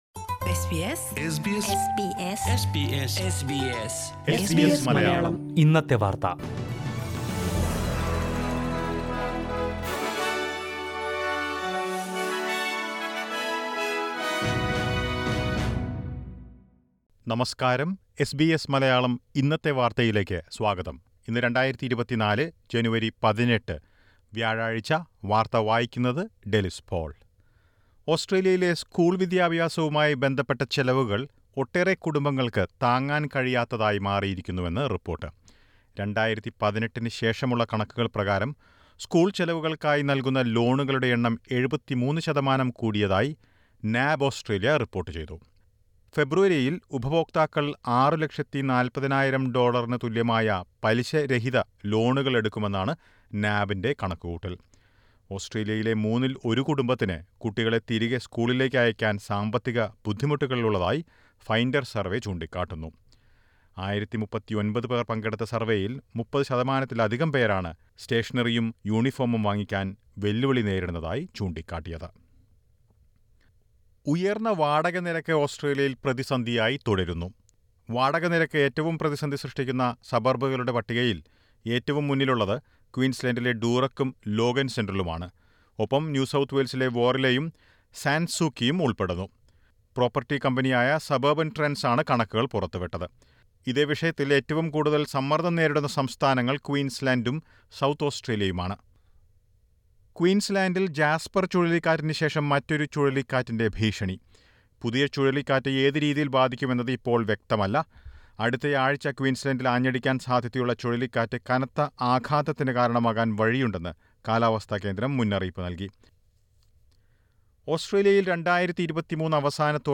2024 ജനുവരി 18ലെ ഓസ്‌ട്രേലിയയിലെ ഏറ്റവും പ്രധാനപ്പെട്ട വാര്‍ത്തകള്‍ കേള്‍ക്കാം.